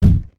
snd_enemypoof.ogg